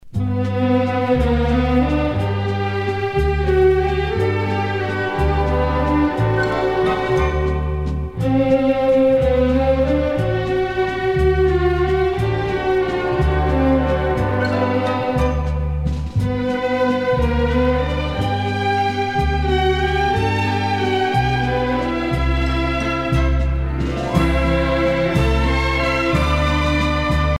danse : valse viennoise
Pièce musicale éditée